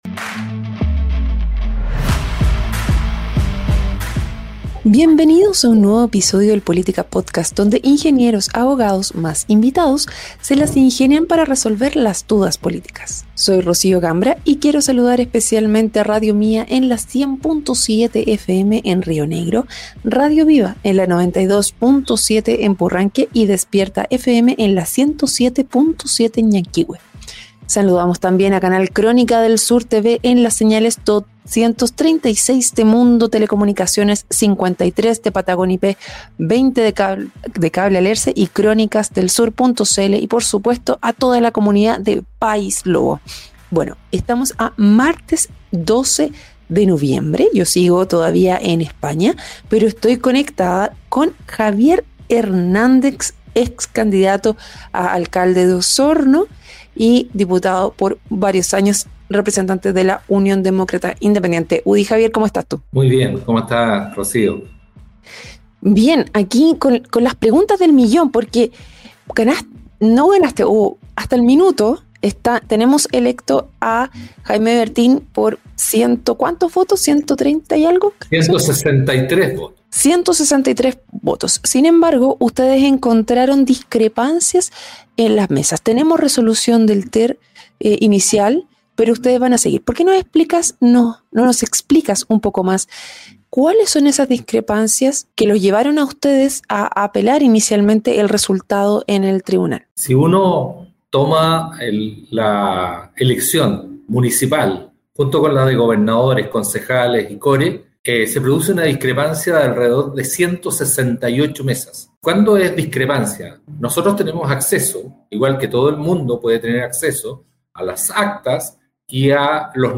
En una reciente entrevista en el "Política Podcast", Javier Hernández, ex candidato a alcalde de Osorno y ex diputado, abordó las discrepancias que él y su equipo han encontrado en el conteo de votos en las pasadas elecciones municipales.